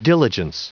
Prononciation du mot diligence en anglais (fichier audio)
Prononciation du mot : diligence